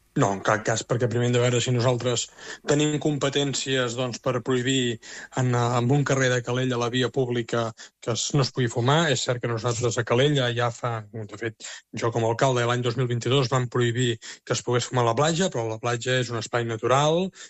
En una entrevista a l’espai Ona Maresme, Buch ha defensat l’actuació del govern i ha acusat els Comuns de buscar només “titulars”.